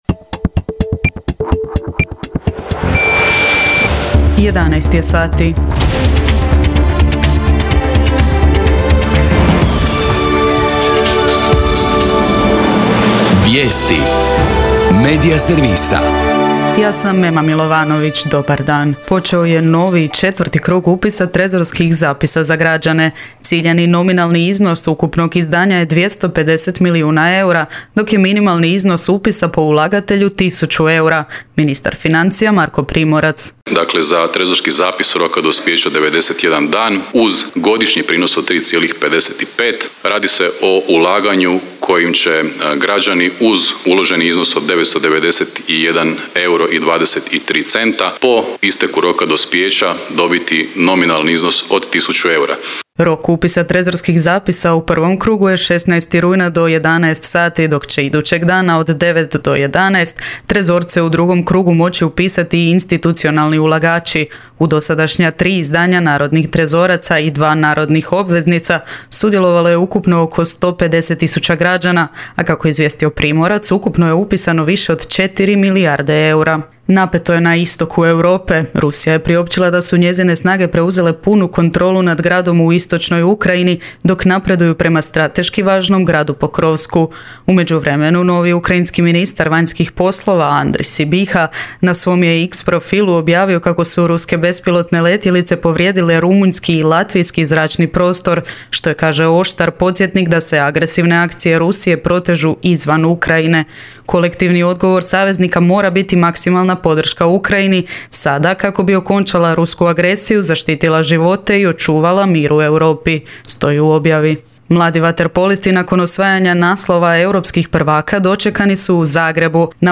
VIJESTI U 11